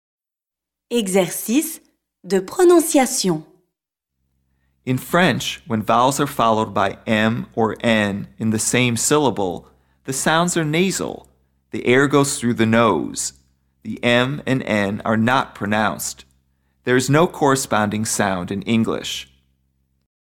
PRONONCIATION
In French, when vowels are followed by “m” or “n” in the same syllable, the sounds are nasal — the air goes through the nose. The “m” and “n” are not pronounced.